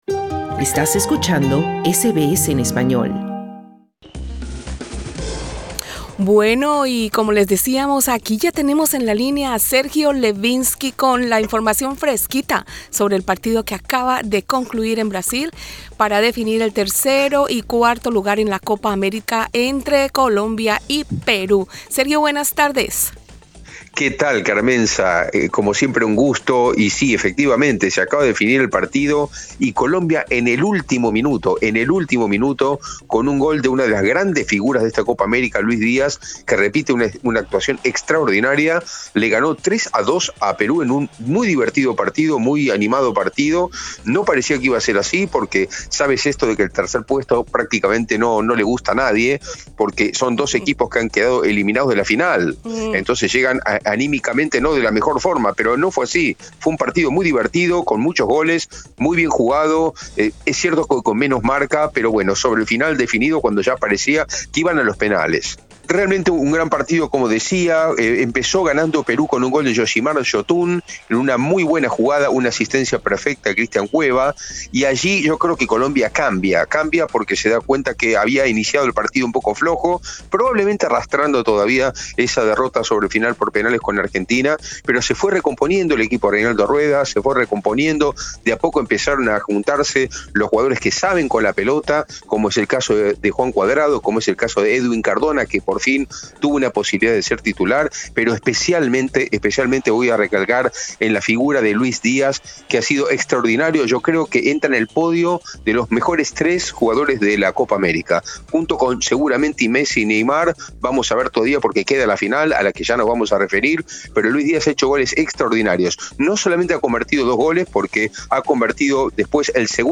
Titulares de deportes del viernes 10 de julio 2021: Colombia es tercero en Copa América-2021 al derrotar 3-2 a Perú.